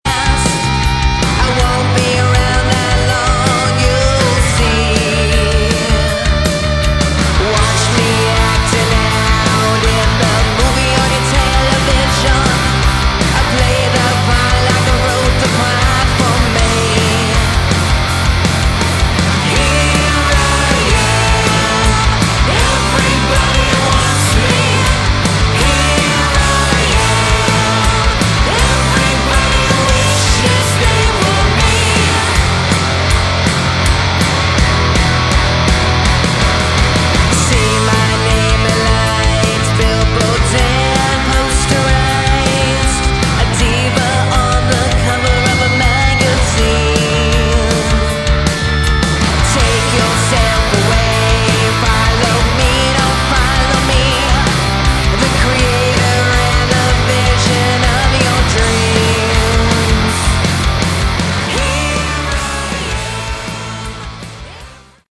Category: Glam/Punk
vocals
guitar, backing vocals, piano
bass, backing vocals
drums, backing vocals